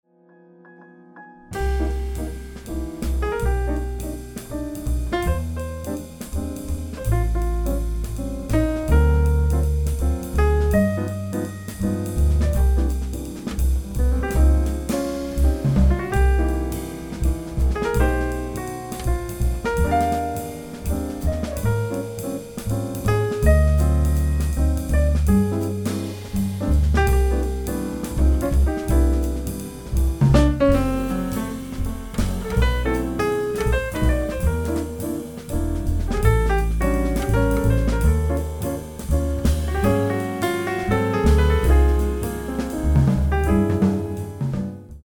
recorded it at the legendary Capital Studios in Hollywood
drums